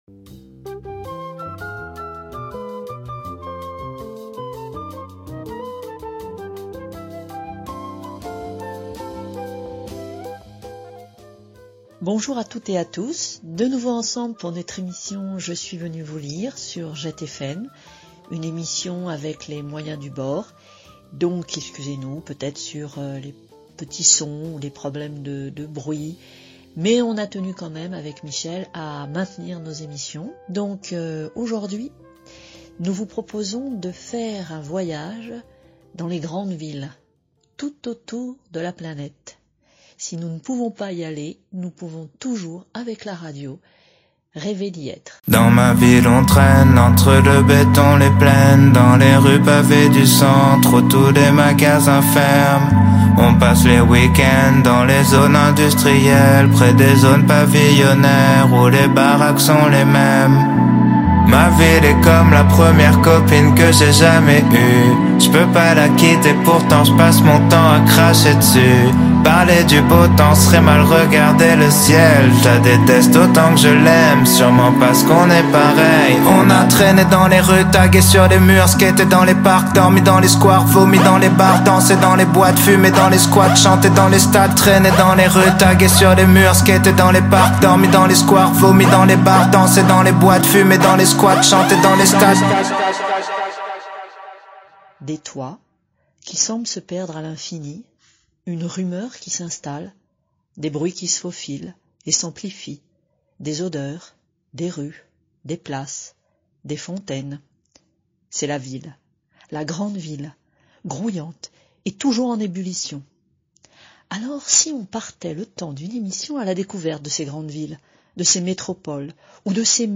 JET FM La radio curieuse 91.2 fm Nantes et agglomération nantaise